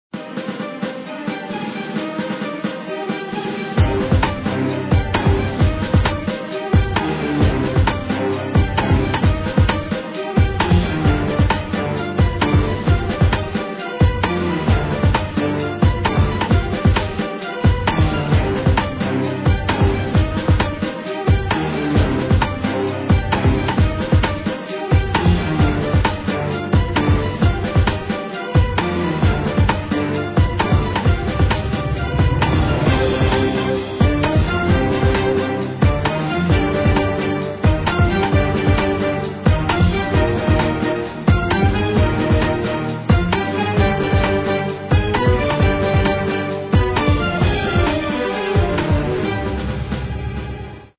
熱血澎湃其實我蠻喜歡的啦~